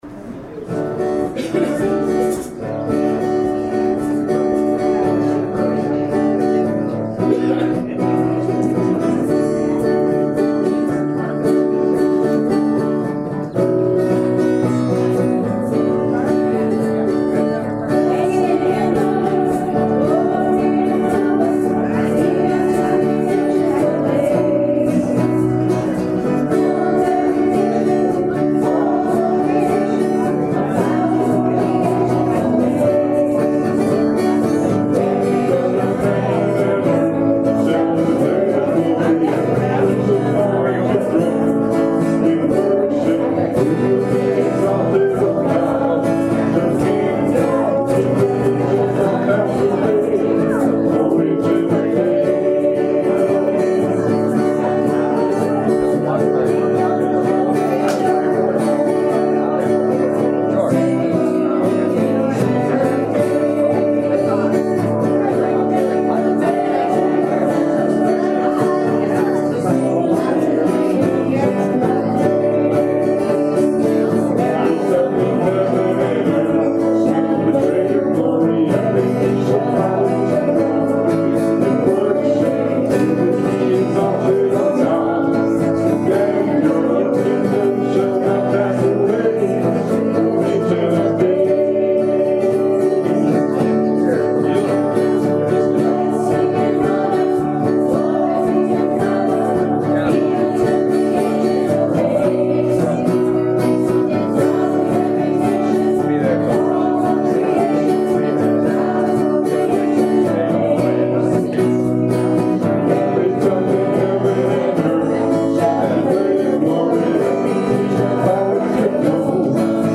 July 3rd, 2016 Service + Communion Podcast
Welcome to the July 3rd, 2016 Service + Communion Podcast.